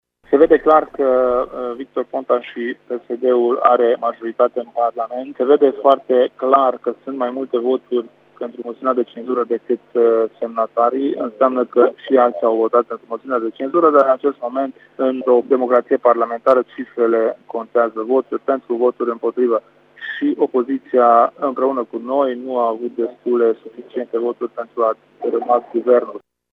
Numărul de voturi „pentru” arată clar că nu doar PNL-işti au votat pentru moţiune, a spus însă preşedintele UDMR, Kelemen Hunor: